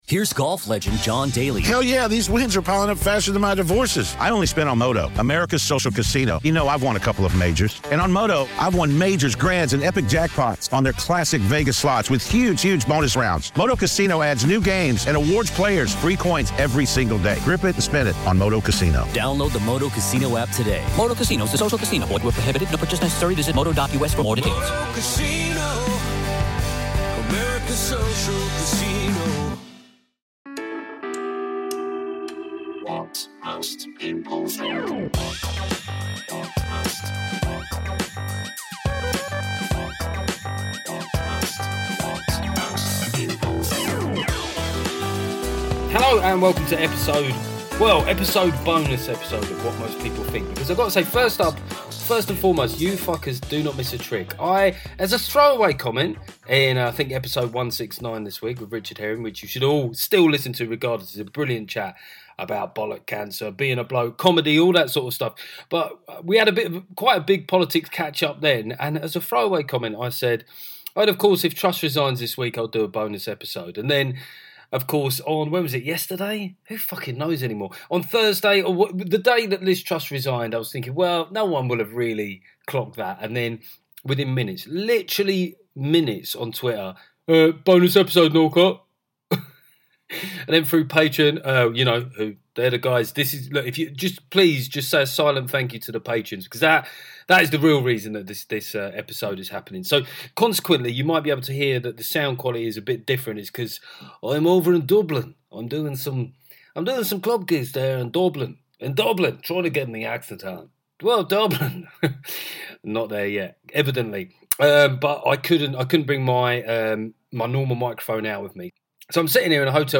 Apologies for the sound quality but i was holed up in a hotel room in Dublin. I pour ever YET ANOTHER mental few days in British politics.